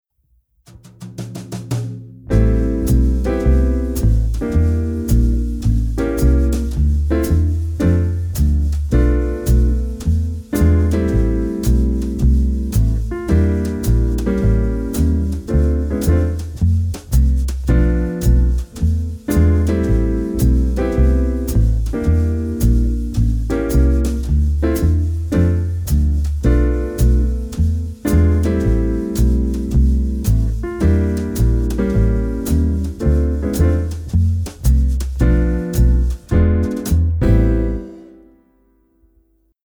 Focus on the bas, it’s often playing the root.
Modulation to 4th step
C instrument (demo)
All modulations are using the 2-5-1 cadence.